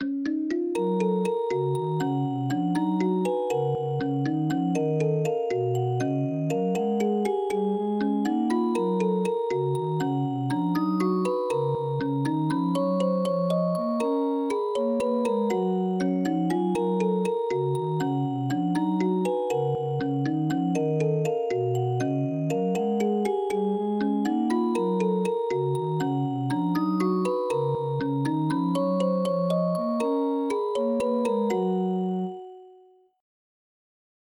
MIDI Composition
They are short and experimental.
A looping song created for a playful website featuring an adorable puppy character I created.